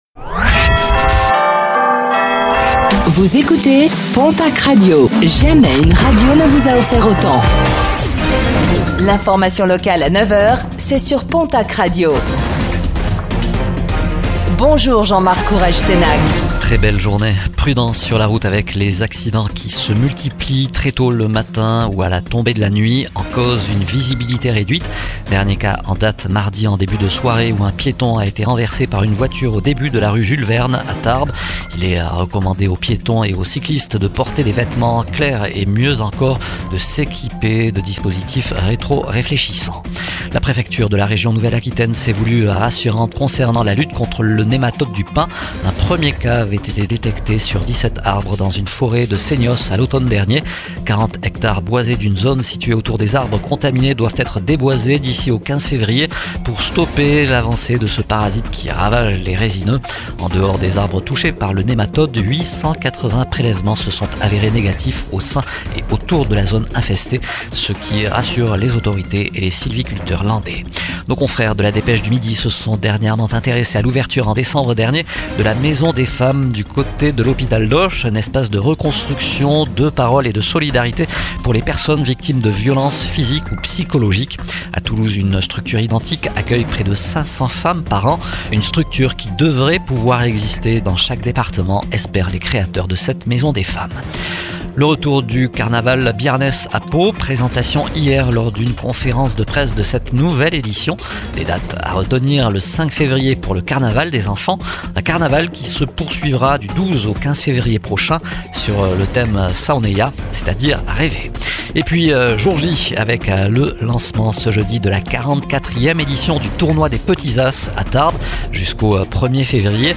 09:05 Écouter le podcast Télécharger le podcast Réécoutez le flash d'information locale de ce jeudi 22 janvier 2026